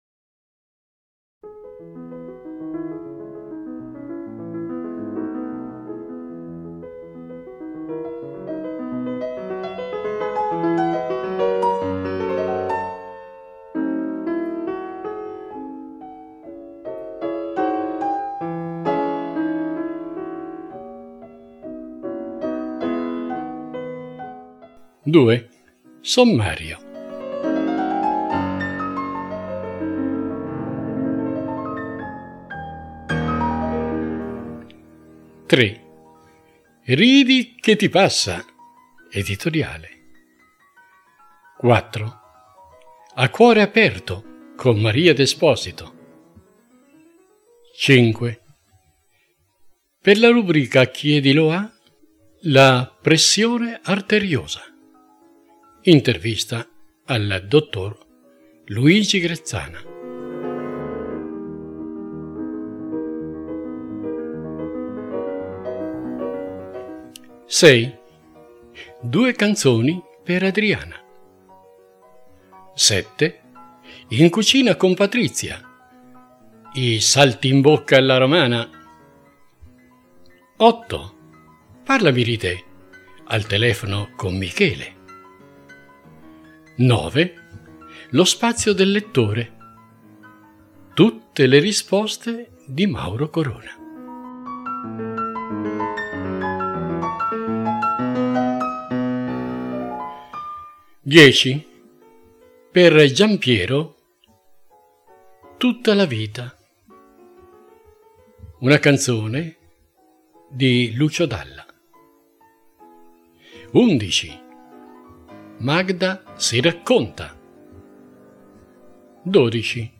Musica per tutti richiesta e proposta dai nostri abbonati vanno a completare + di cinque ore di ascolto vario, interessante, dai toni pacati e sereni.